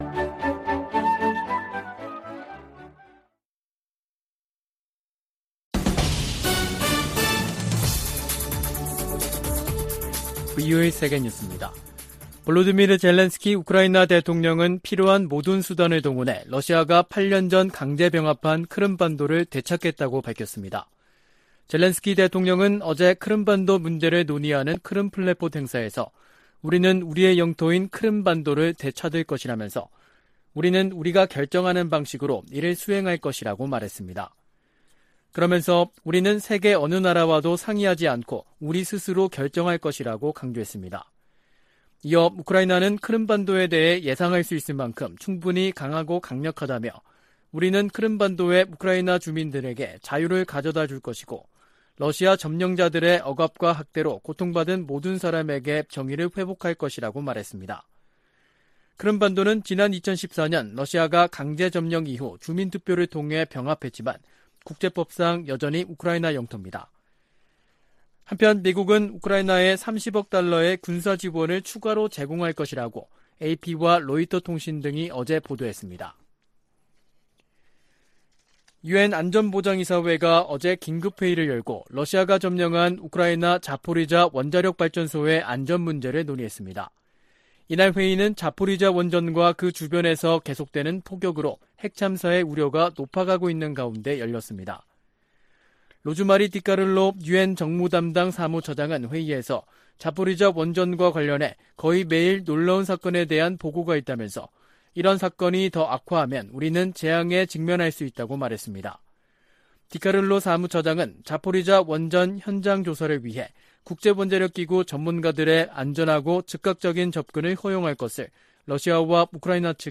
VOA 한국어 간판 뉴스 프로그램 '뉴스 투데이', 2022년 8월 24일 3부 방송입니다. 제10차 핵확산금지조약(NPT) 평가회의가 한반도의 완전한 비핵화를 지지하는 내용이 포함된 최종 선언문 초안을 마련했습니다. 에드워드 마키 미 상원의원은 아시아태평양 동맹과 파트너들이 북한의 핵 프로그램 등으로 실질적 위협에 직면하고 있다고 밝혔습니다. 미국 정부가 미국인의 북한 여행 금지조치를 또다시 연장했습니다.